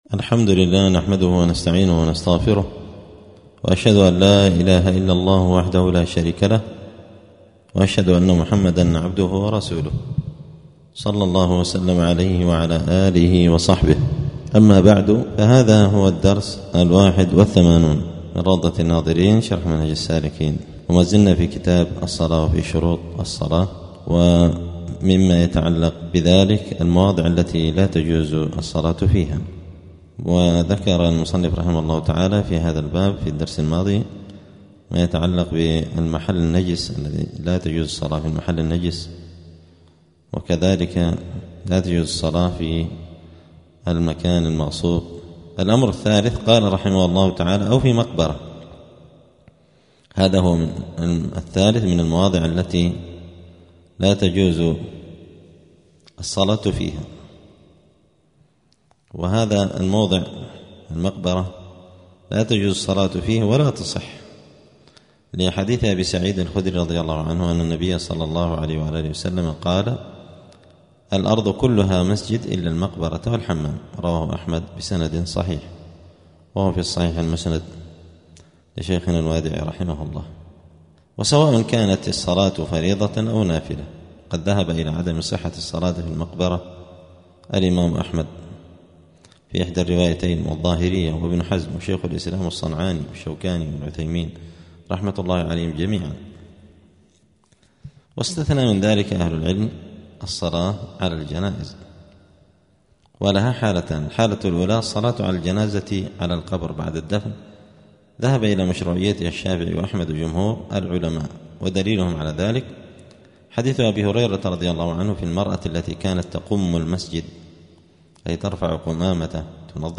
دار الحديث السلفية بمسجد الفرقان قشن المهرة اليمن 📌الدروس اليومية